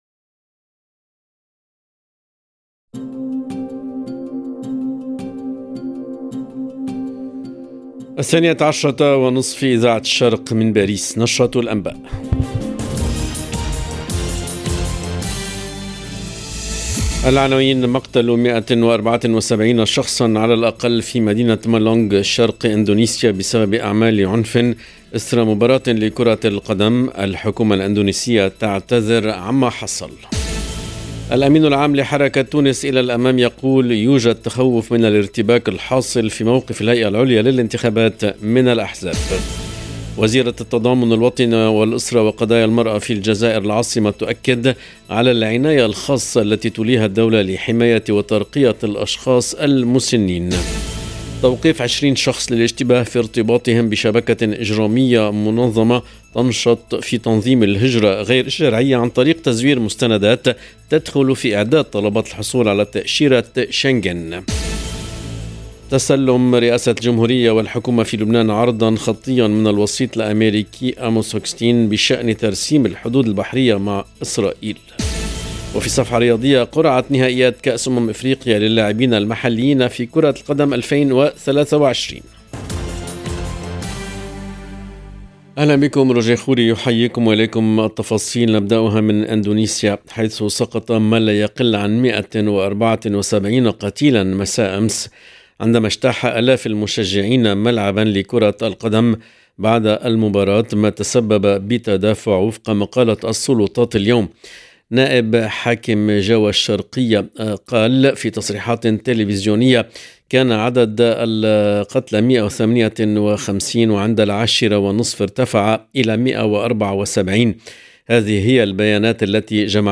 LE JOURNAL EN LANGUE ARABE DE MIDI 30 DU 2/10/22